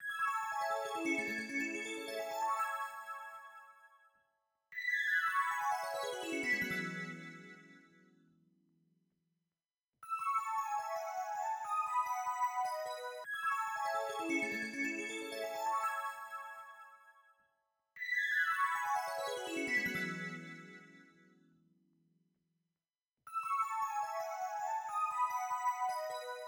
02 bellpad A.wav